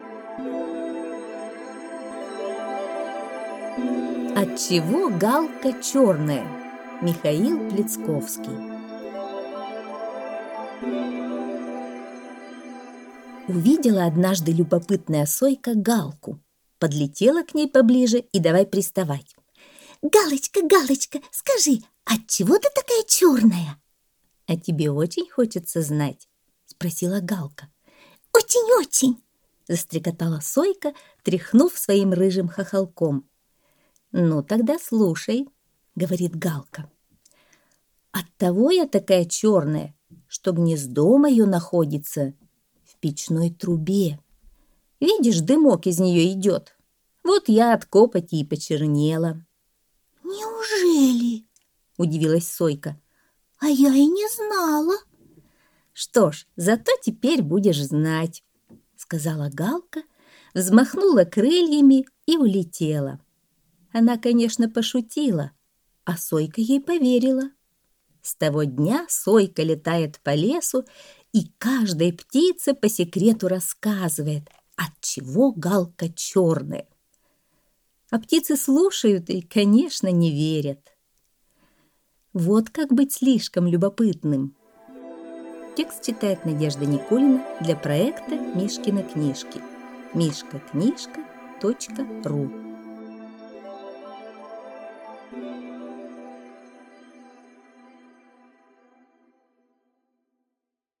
Аудиосказка «Отчего галка черная»